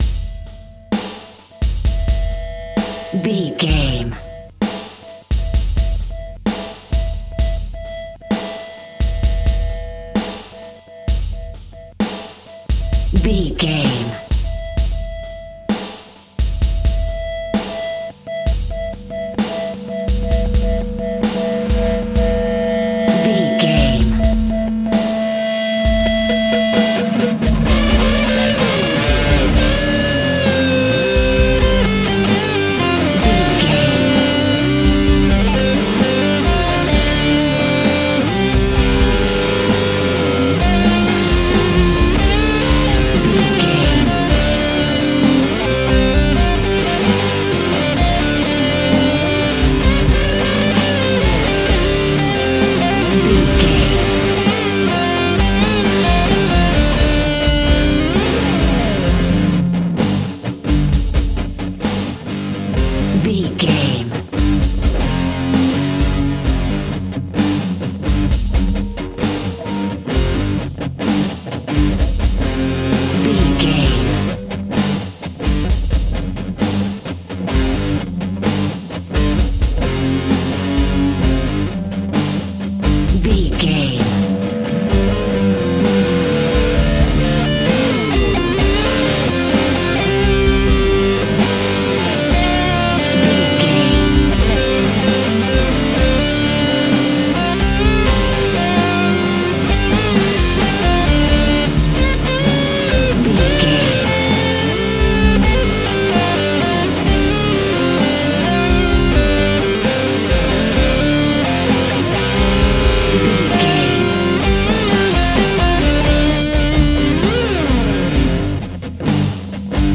Old School Metal.
Epic / Action
Aeolian/Minor
D
Slow
heavy rock
distortion
Instrumental rock
drums
bass guitar
electric guitar
piano
hammond organ